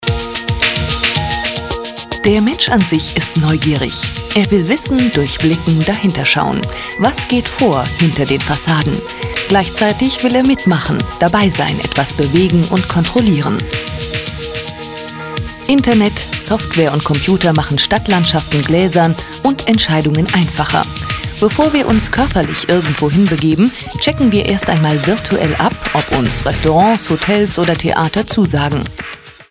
Kein Dialekt
Sprechprobe: eLearning (Muttersprache):
female german voice over talent